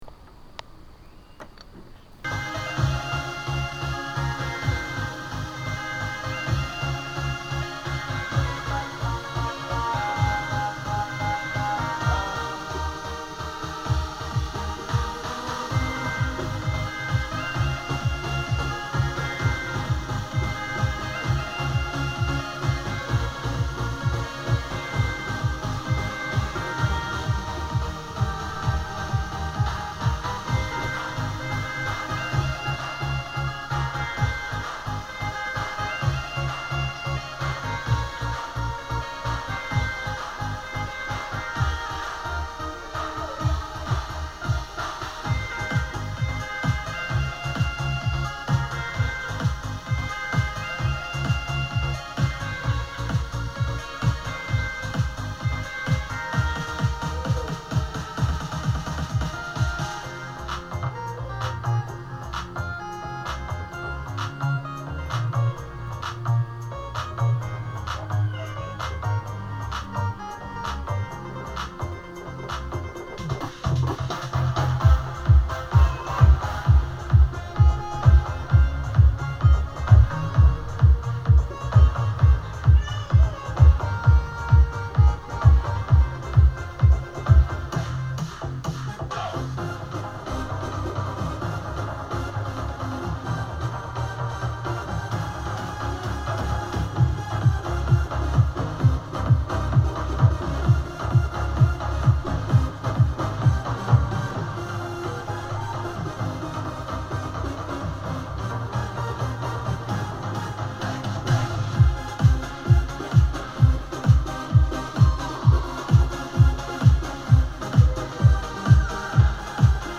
למה הקלטה חיצונית?